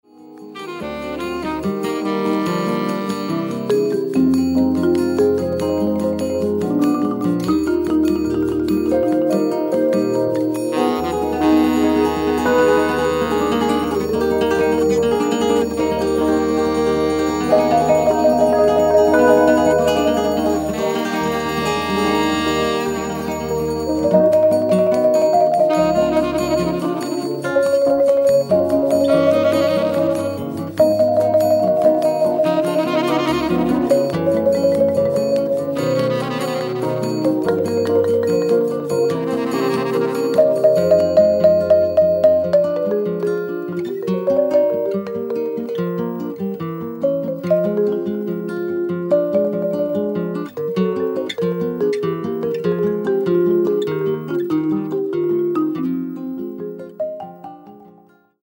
jazz
With its smooth and relaxing jazz sounds